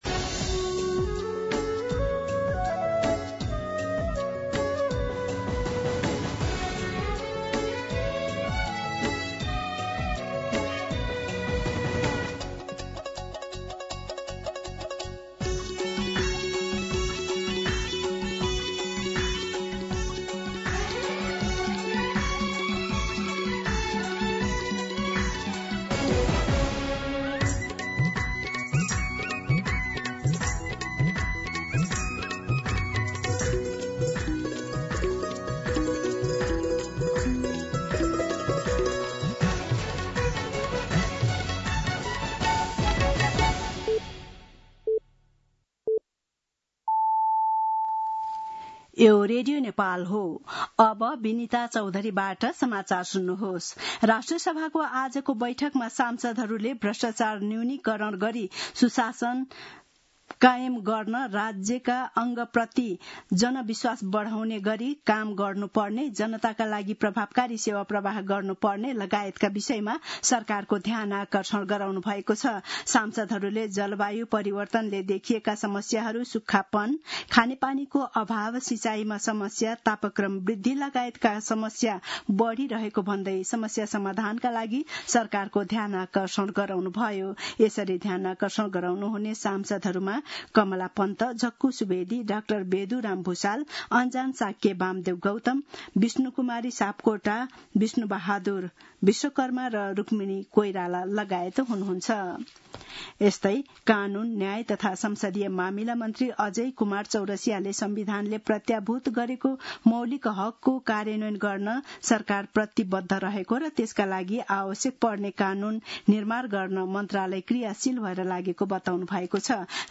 दिउँसो ४ बजेको नेपाली समाचार : ११ साउन , २०८२
4-pm-Nepali-News-4.mp3